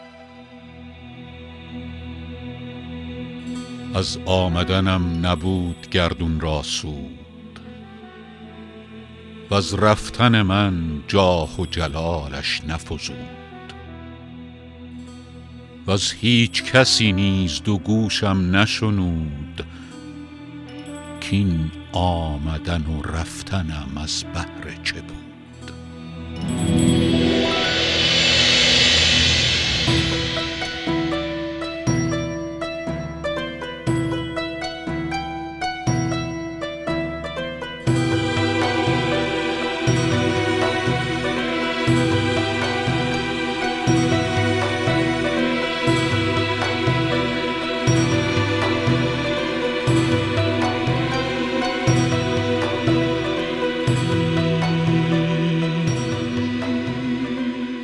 رباعی ۳ به خوانش فریدون فرح‌اندوز